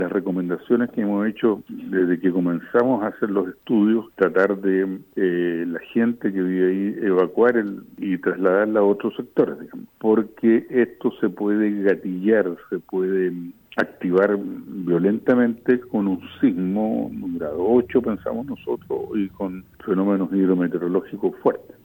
Por su parte, el director regional de Sernageomin, Carlos Johnson, afirmó que han recomendado que las familias que habitan en Las Lajas sean reubicadas a zonas más seguras, debido a que una remoción en masa se podría gatillar con un fuerte sismo o con intensas lluvias.